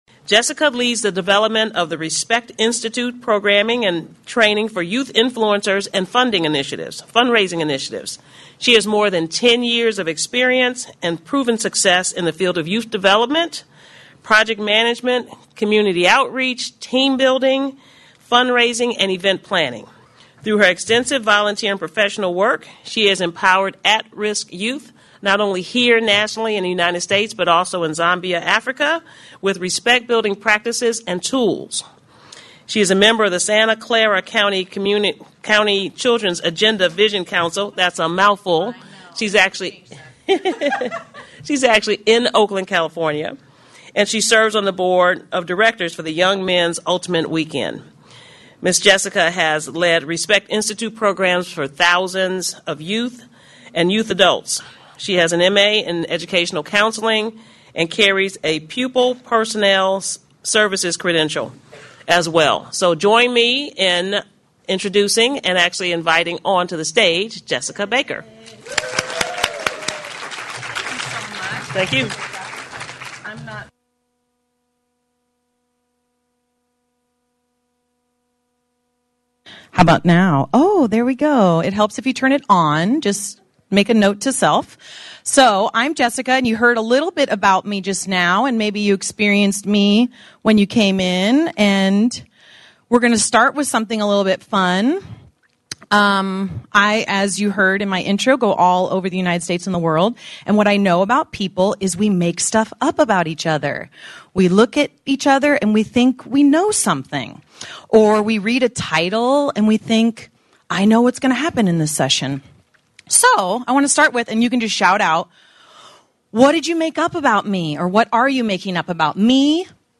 In this interactive session, learn the secrets featured by CNN, USA Today, Glamour, and Teen Vogue to getting the life, relationships, work and health you want. The Respect Basics will show you how to finally make self-respect the foundation of your decisions, self-care, relationships and leadership path.